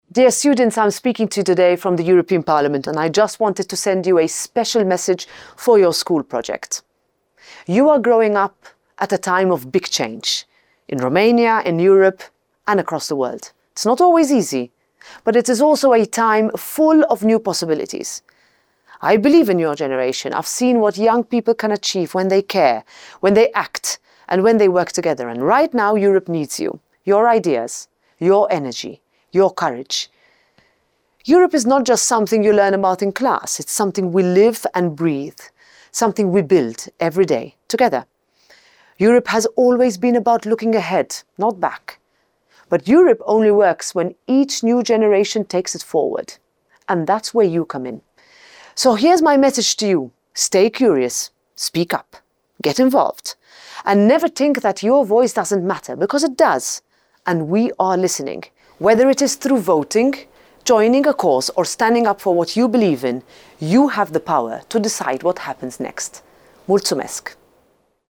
Mesajul președintei Parlamentului European, Roberta Metsola: